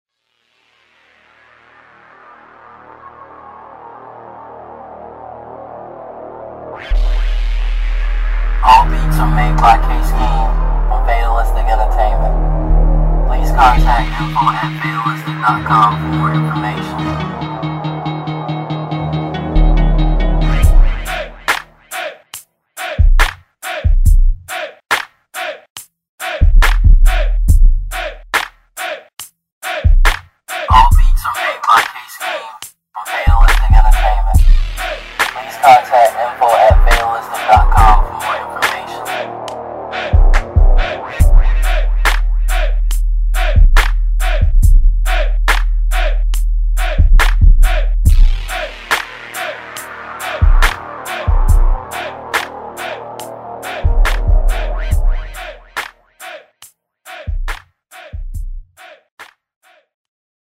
This beat is mixed and mastered in Logic Pro on an iMac.